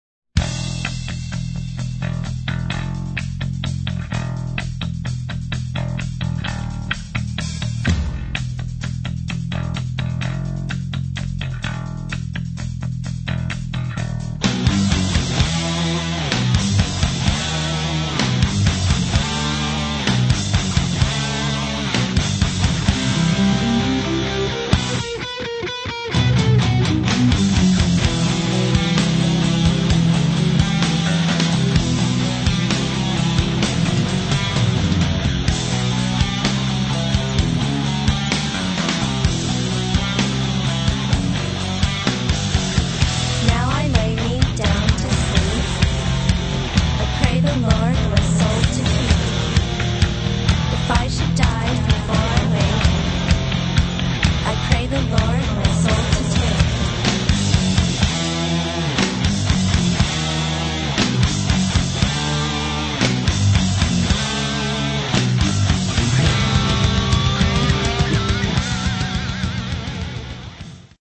Lead Vocals and Lead and Rhythm Guitars
Bass Guitar
Lead Drums
Background Vocals
Recorded and Mixed at: The Enterprise, Burbank